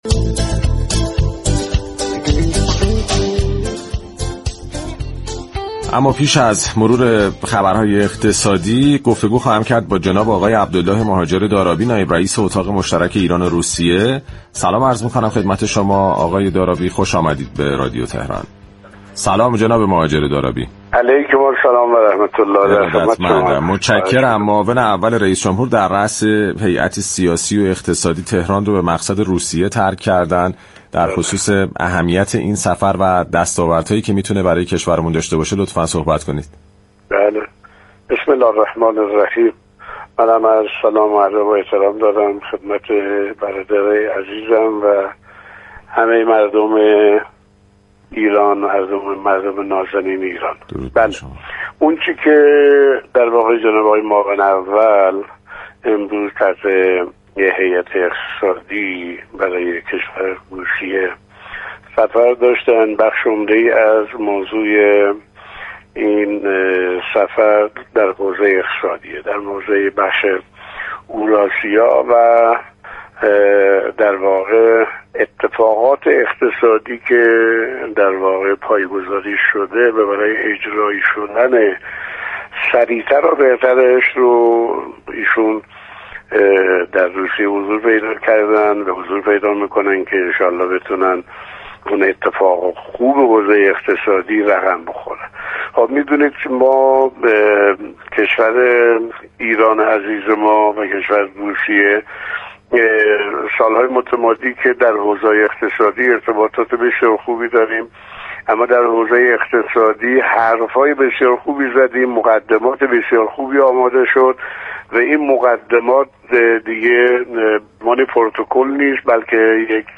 در گفت‌وگو با برنامه «بازار تهران» رادیو تهران